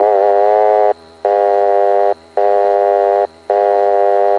复古主义机器报警器